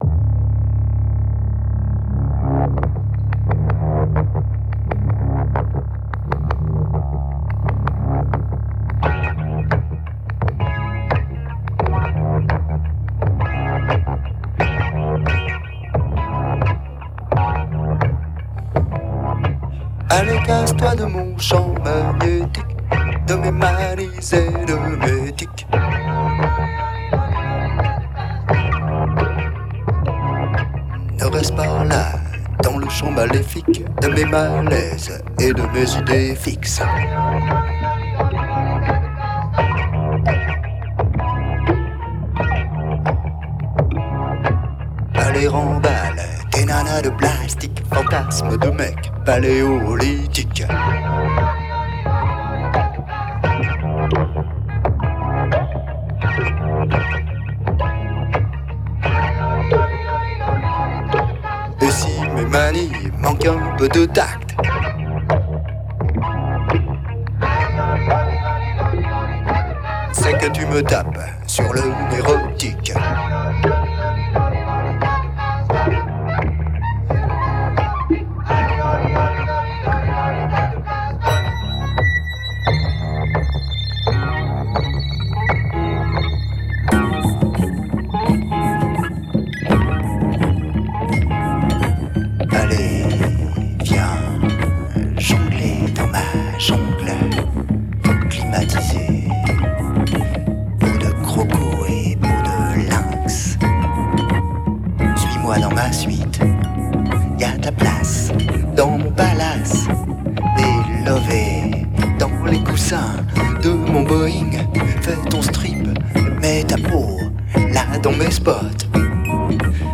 Cult indus / minimal synth from France !